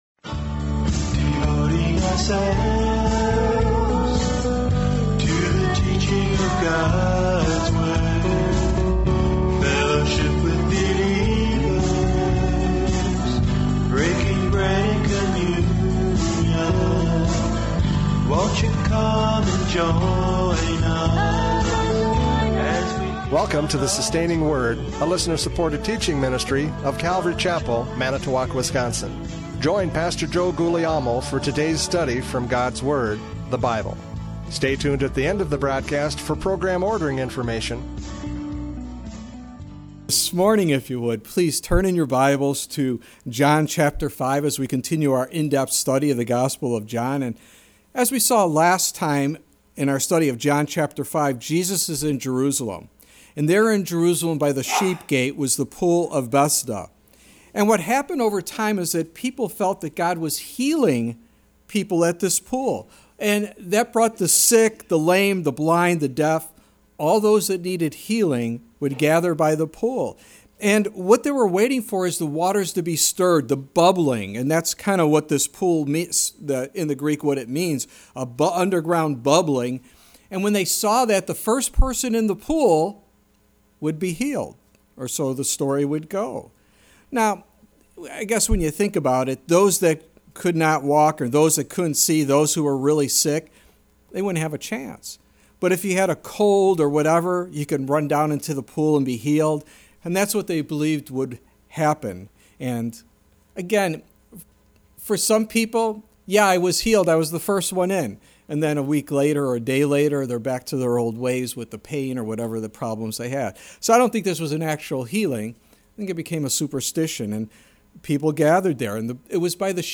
John 5:10-16 Service Type: Radio Programs « John 5:1-9 Do You Want to be Made Well?